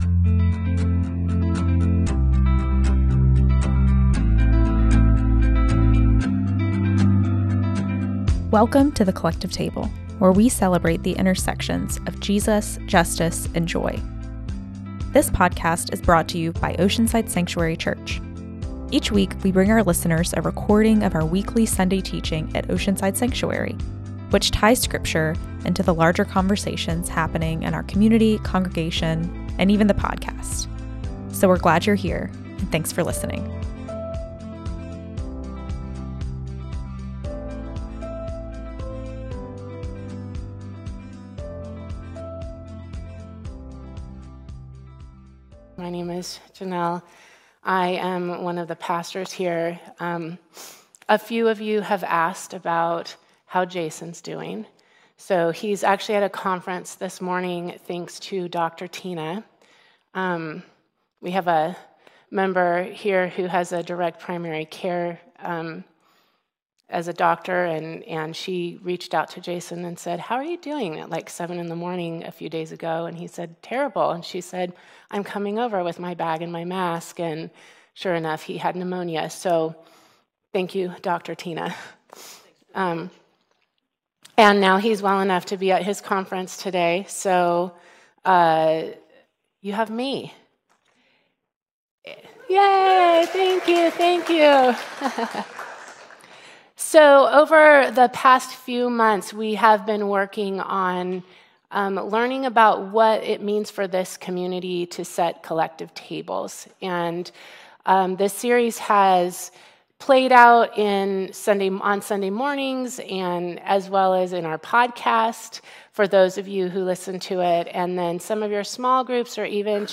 1 OSC Sunday Teaching - "The Expanding Feast" - November 24th, 2014 27:04 Play Pause 3d ago 27:04 Play Pause Afspil senere Afspil senere Lister Like Liked 27:04 Welcome to The Collective Table, where we celebrate the intersections of Jesus, justice, and joy!